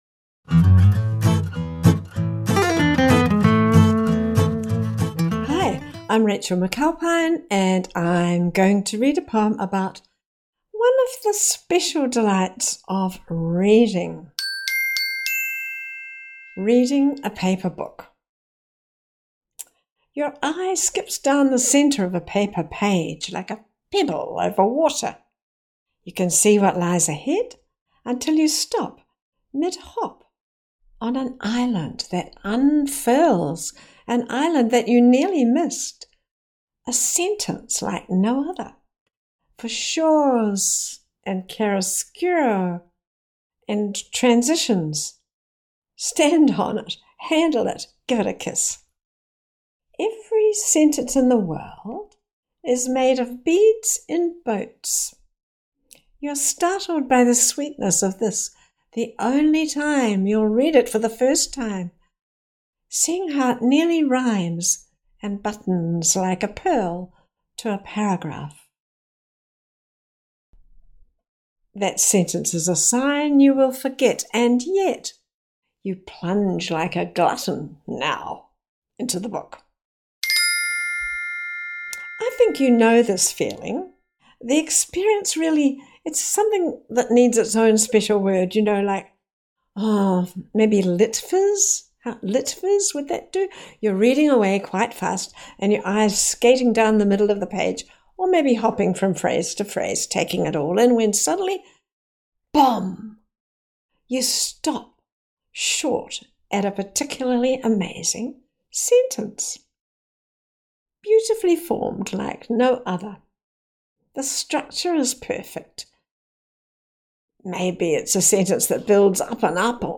read and respond to new poems